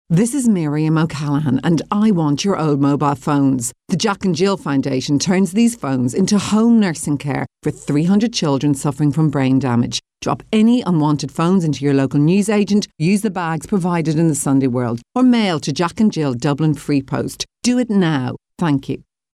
Miriam O'Callaghan donates her voice to make SOS phone ad for Jack & Jill
Our thanks to Miriam O’Callaghan who kindly donated her voice to making a radio ad for Jack & Jill’s SOS campaign to raise 250,000 phones by March 2011.
Miriam-OCallaghan-radio-ad-with-Sunday-World-mention.mp3